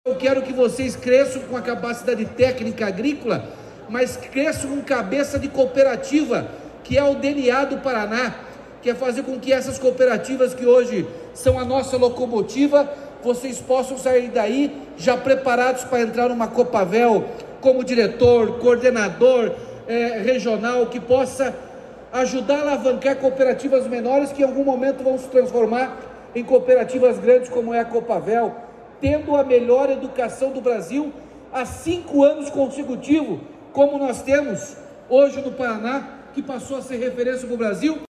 Sonora do governador Ratinho Junior sobre a nova plantadeira desenvolvida pela Horsch